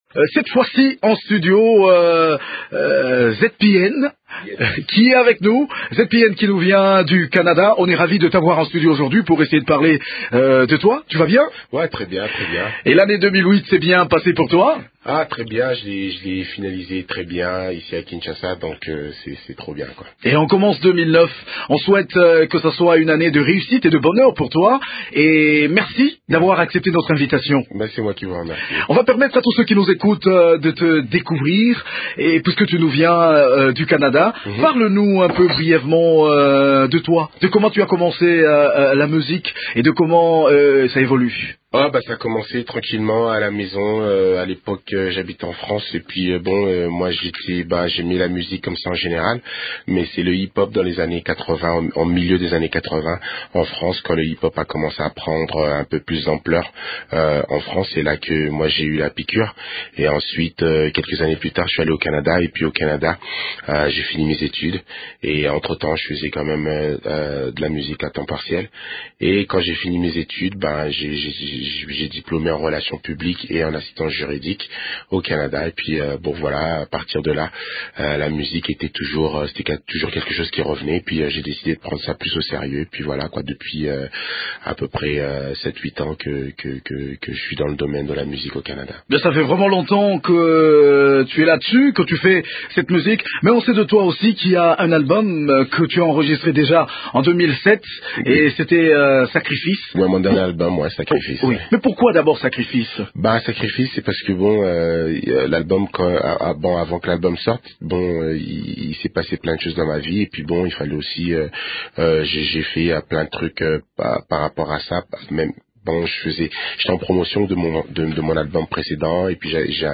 interview exclusive